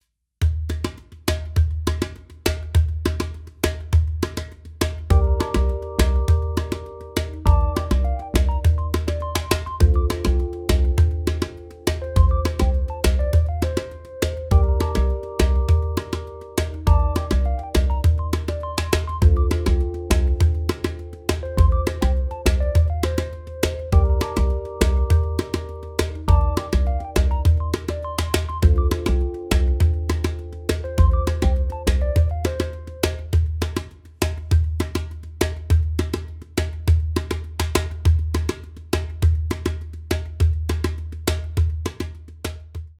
MEINL Percussion Travel Series Djembe 12" - Simbra (PADJ5-L-F)